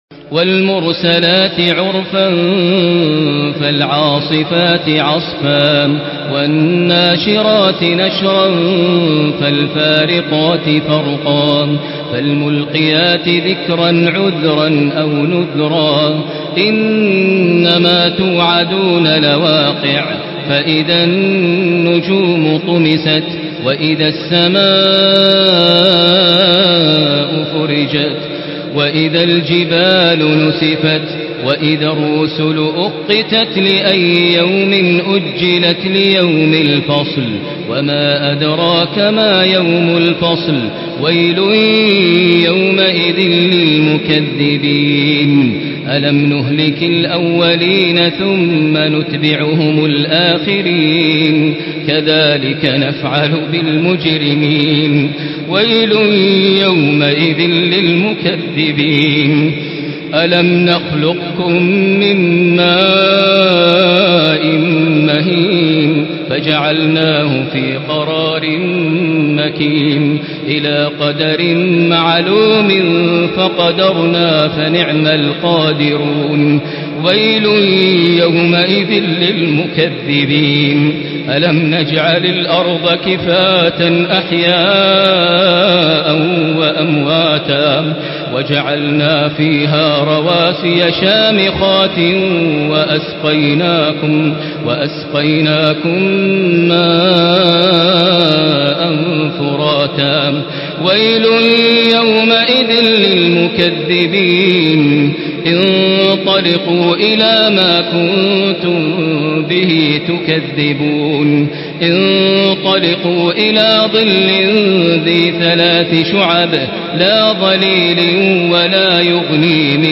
Surah আল-মুরসালাত MP3 in the Voice of Makkah Taraweeh 1435 in Hafs Narration